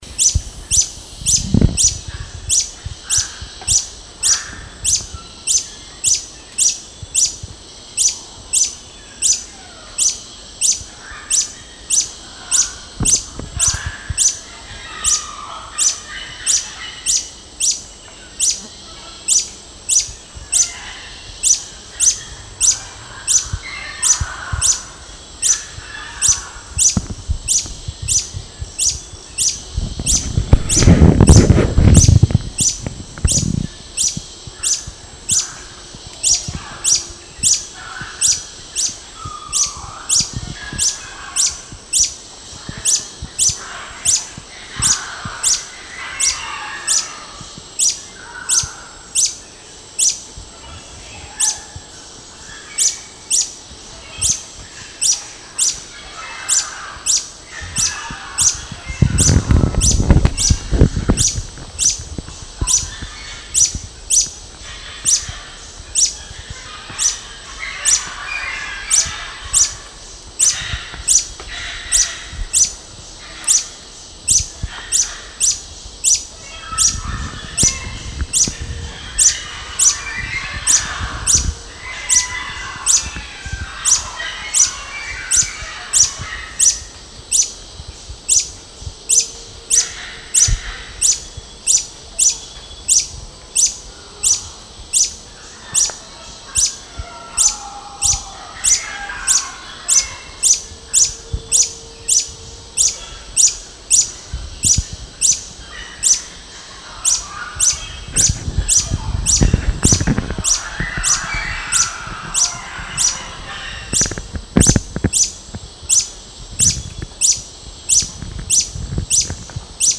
Phaethornis-longirostris-154072.wav